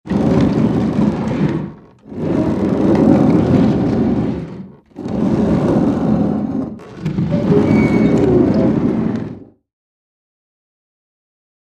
Gate, Metal | Sneak On The Lot
Door; Metal Rolling Door Rolls Back And Forth In Track.